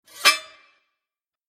جلوه های صوتی
دانلود آهنگ دعوا 34 از افکت صوتی انسان و موجودات زنده
دانلود صدای دعوا 34 از ساعد نیوز با لینک مستقیم و کیفیت بالا